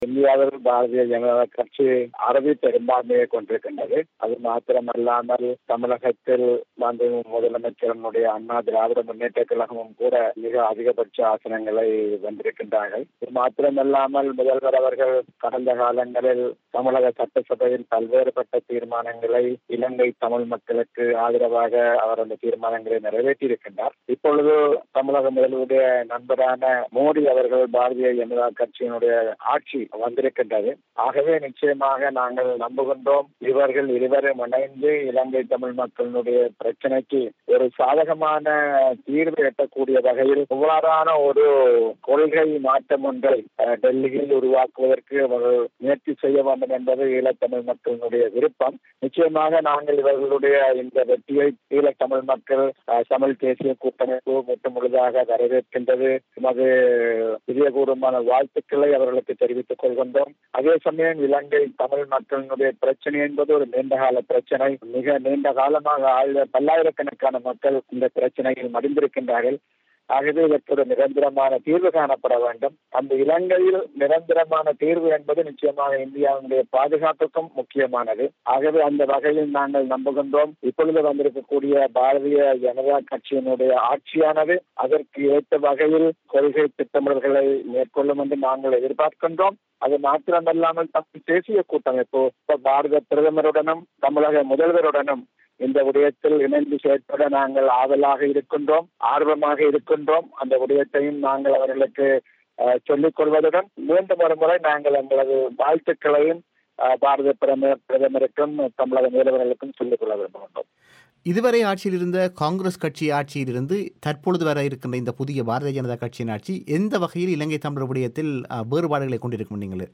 அவர் பிபிசி தமிழோசைக்கு வழங்கிய செவ்வியை இங்கே கேட்கலாம்.